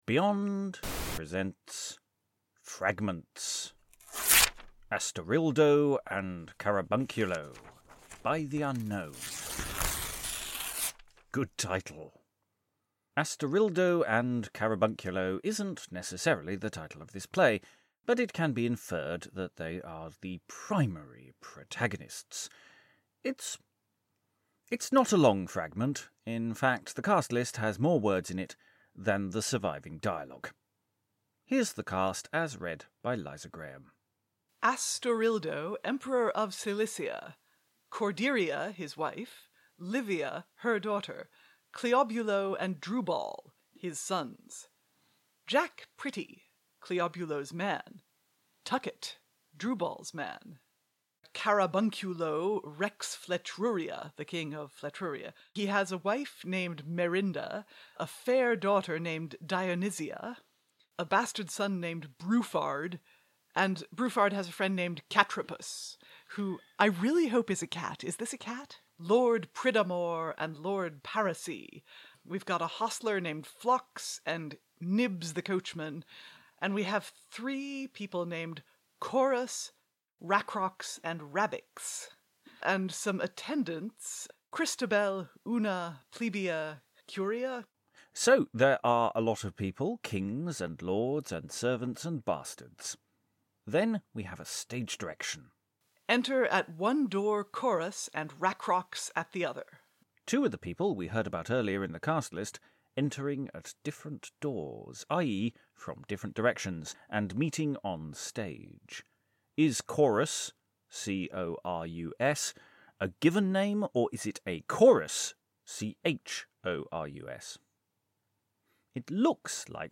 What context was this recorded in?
We recorded the audio for this as part of a test live stream session, shared only with our patrons.